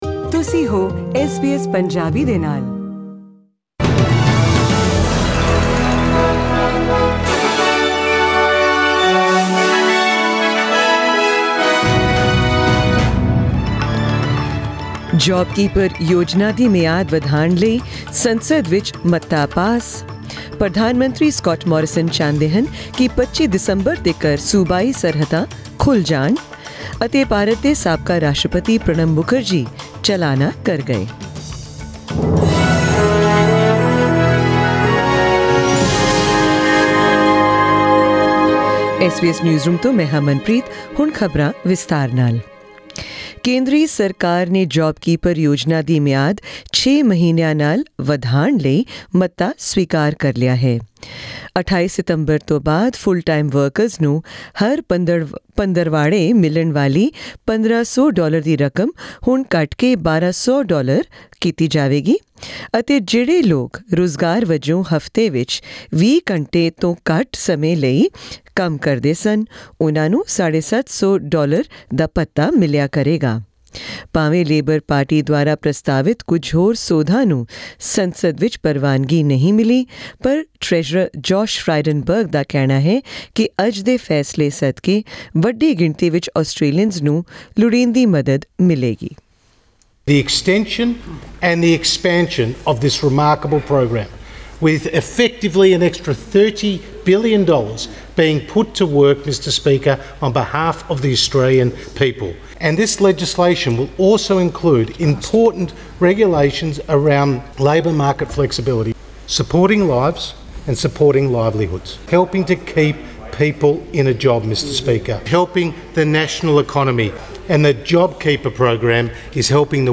In tonight's SBS Punjabi news bulletin, hear about